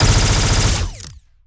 Plasmid Machinegun B
GUNAuto_Plasmid Machinegun B Burst_01_SFRMS_SCIWPNS.wav